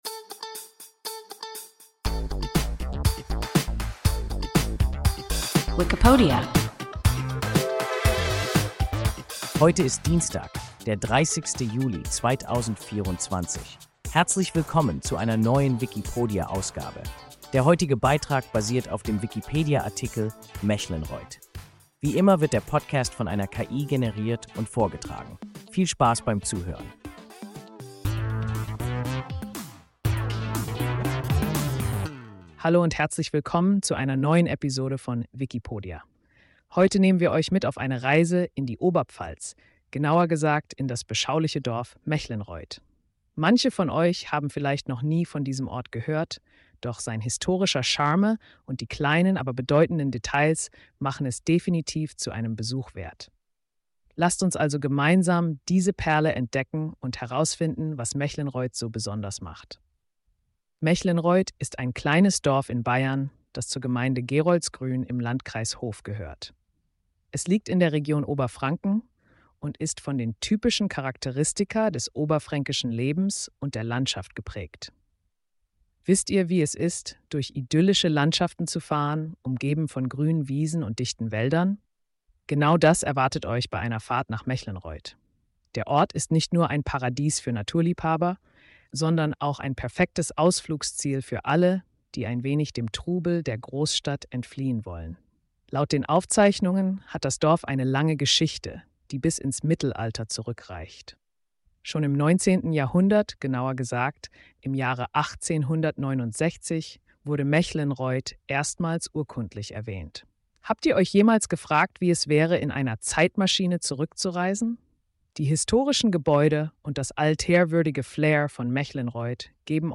Mechlenreuth – WIKIPODIA – ein KI Podcast